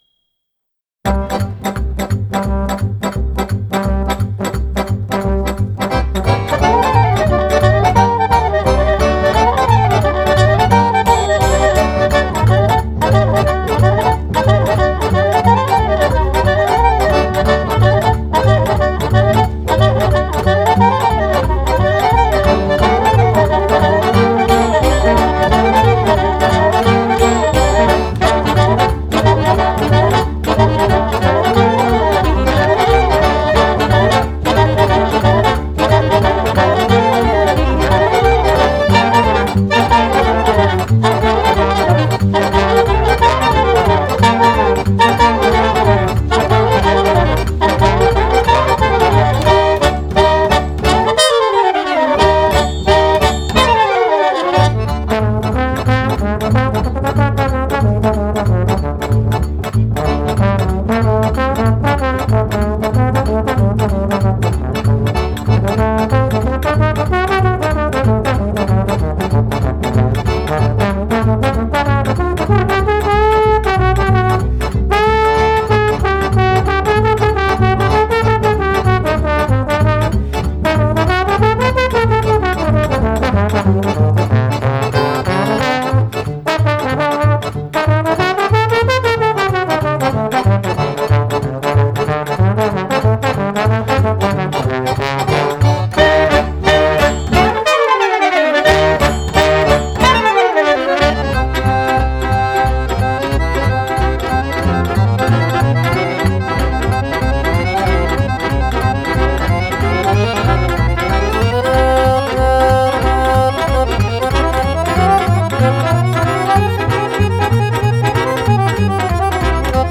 Genre: Klezmer, Folk, World